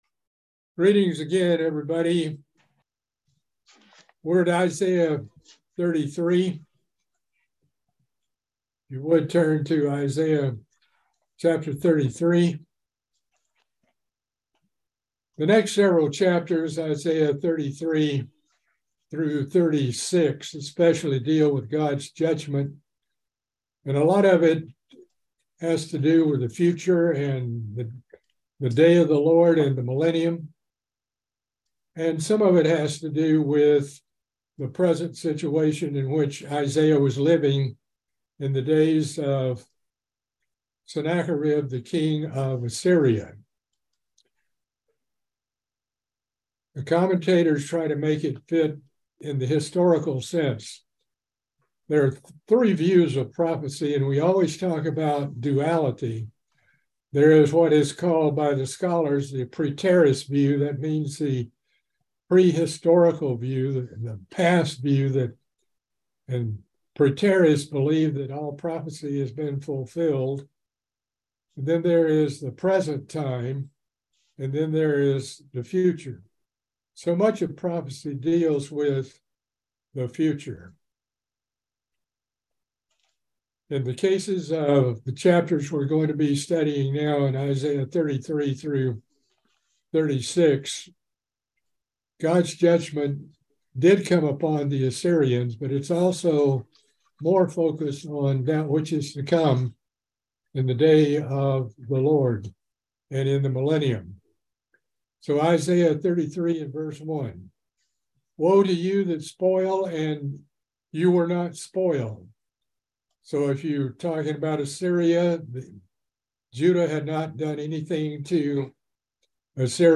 Book of Isaiah Bible Study - Part 24